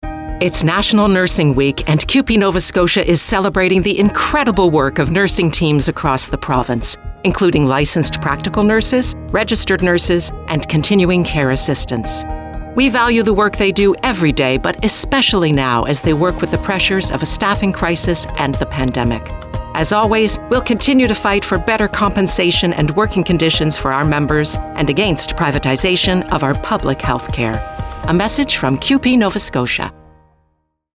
To celebrate, CUPE NS is running a radio ad on stations province-wide.